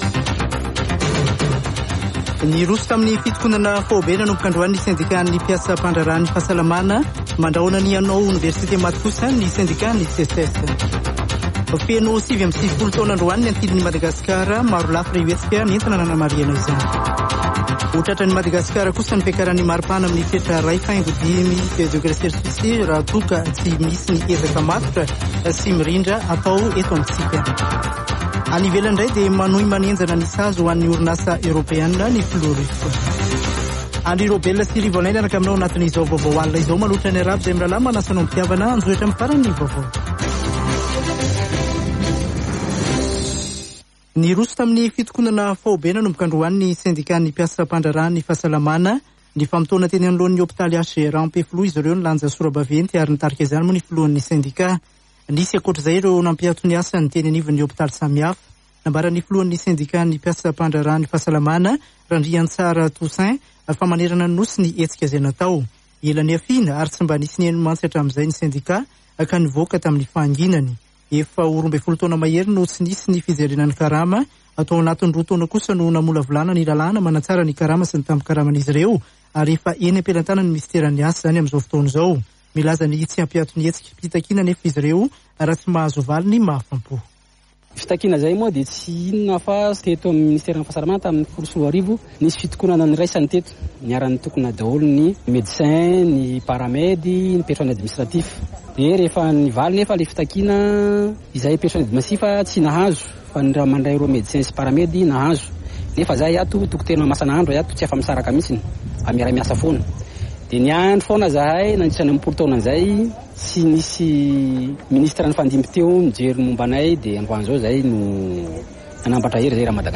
[Vaovao hariva] Alarobia 23 marsa 2022